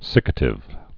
(sĭkə-tĭv)